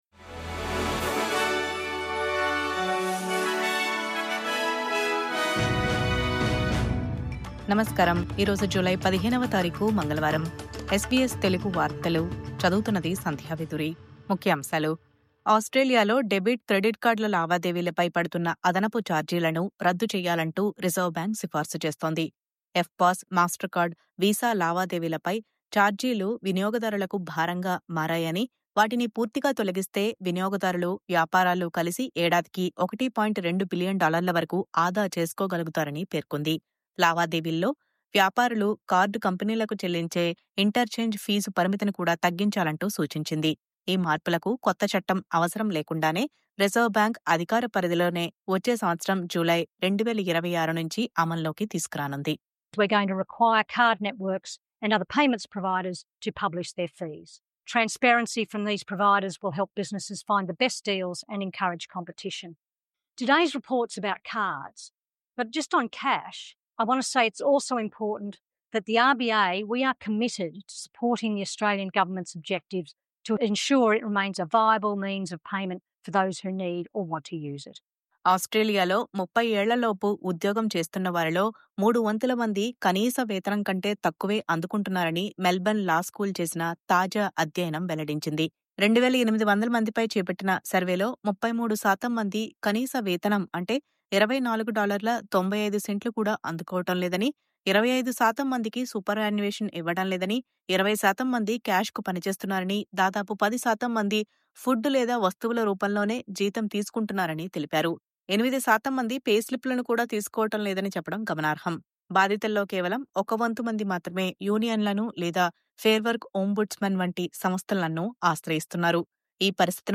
News Update: డెబిట్, క్రెడిట్ కార్డ్ లావాదేవీలపై అదనపు చార్జీలు రద్దు..